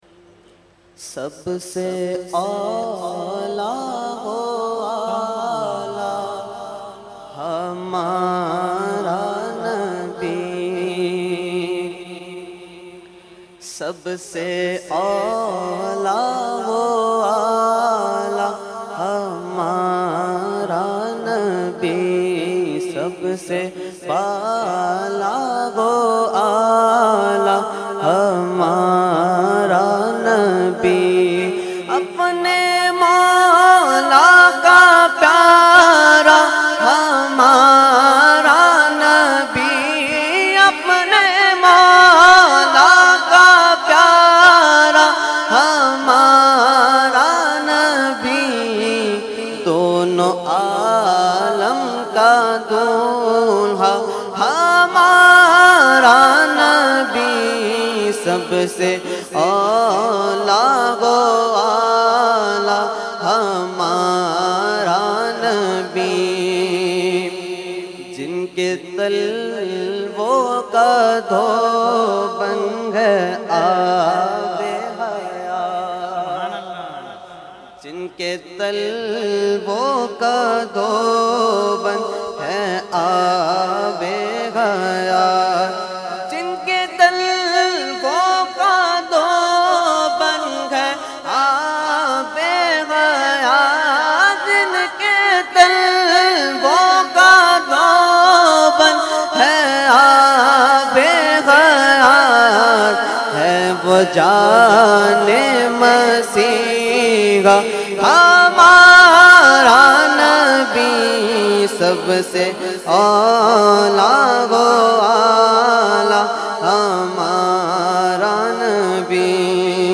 held on 25,26,27 August 2022 at Dargah Alia Ashrafia Ashrafabad Firdous Colony Gulbahar Karachi.
Category : Naat | Language : UrduEvent : Urs Makhdoome Samnani 2022